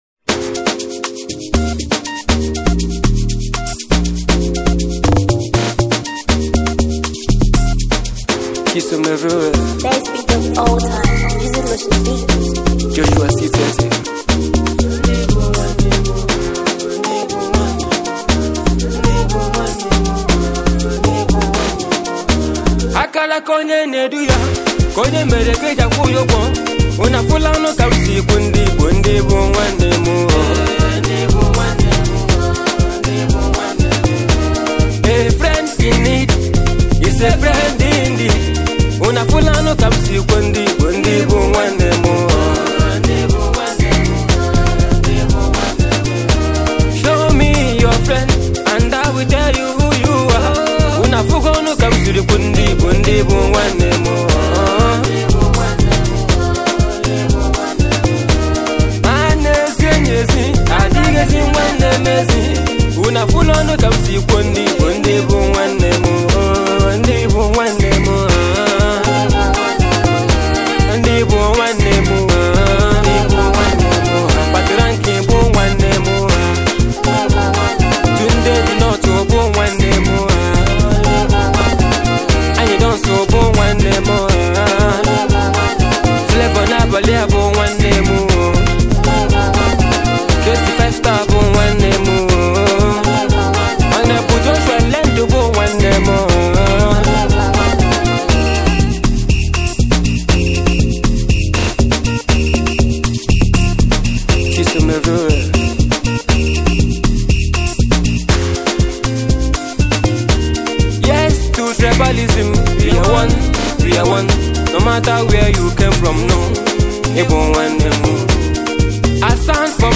With his pleasant sounds